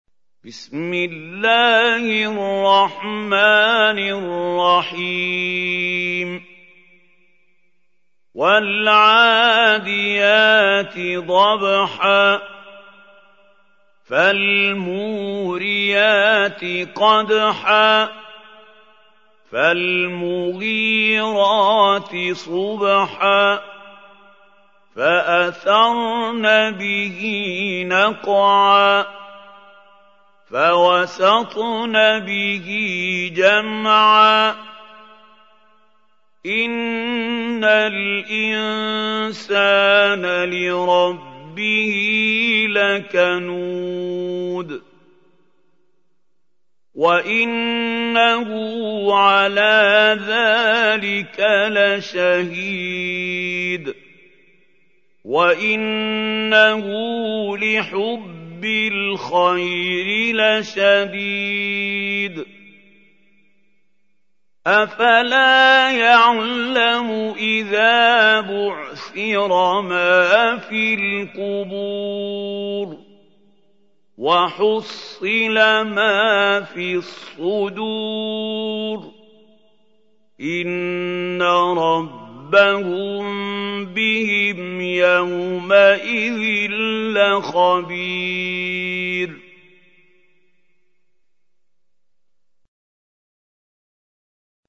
Чтение Корана > ХАЛИЛ ХУСАРИ